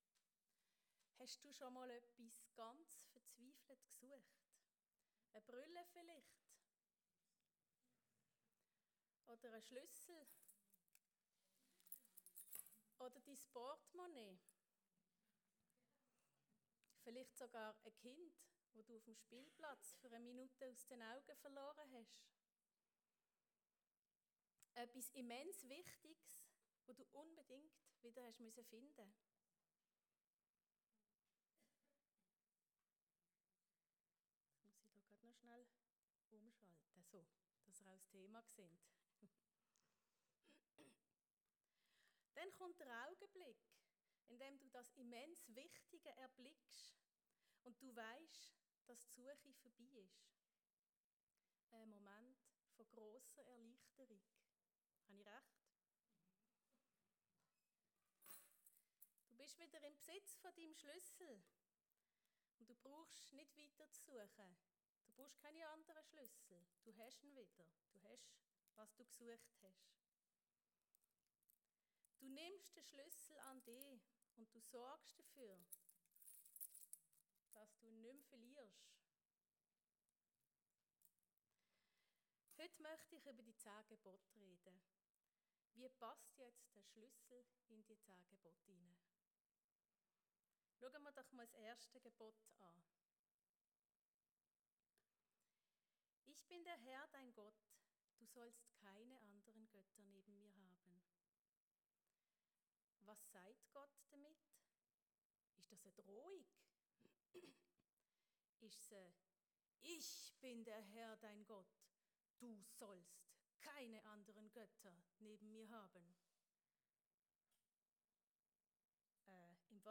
Predigt-Du-sollst-nicht.-Die-zehn-An-Gebote.mp3